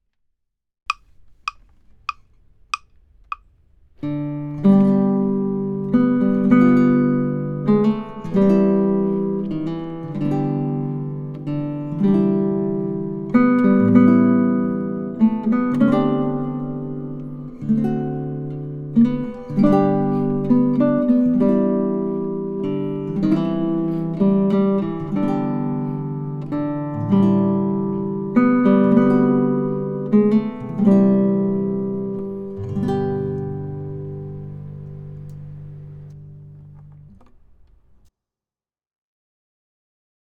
This beautiful folkloric strum allows space for the melody to shine.
Amazing Grace | Simple Strum 3/4 (with melody)